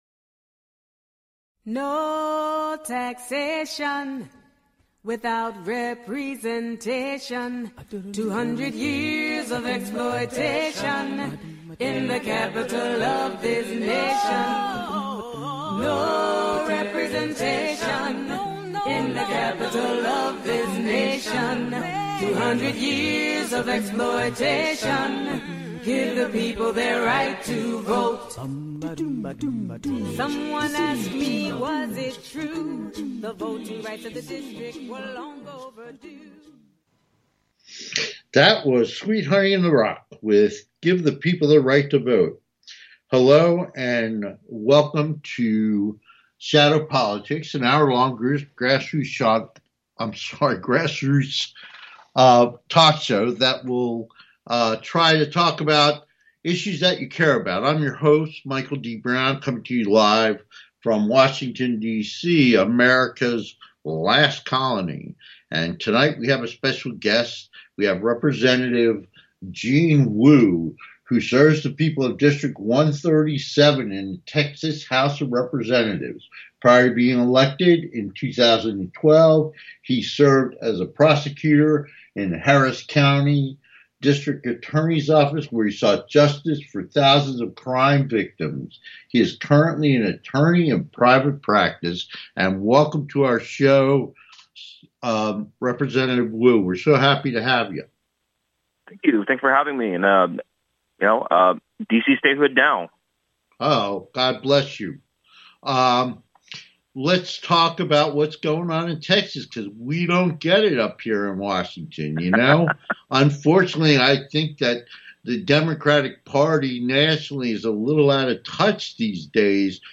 Talk Show Episode
Guest, Gene Wu, State Representative proudly serves the people of District 137 in the Texas House